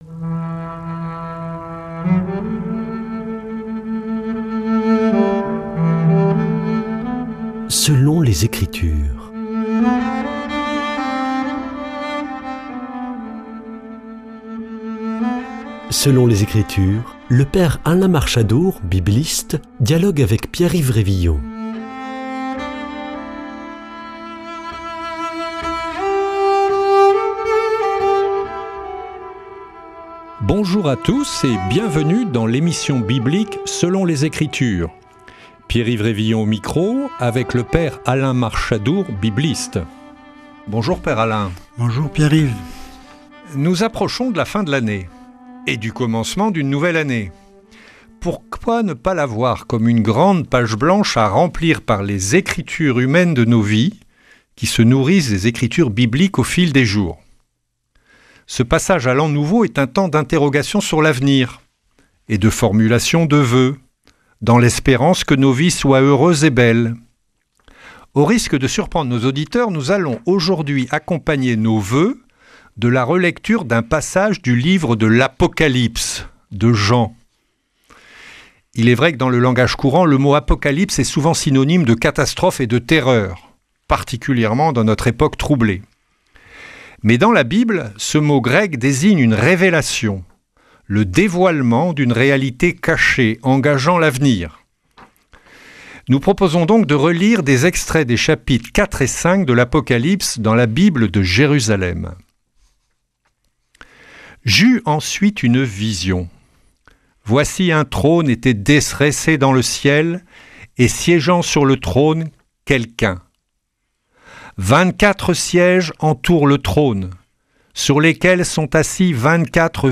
» Une lecture d’extraits des chapitres 4 et 5 de l’Apocalypse pour entrer dans l’an nouveau...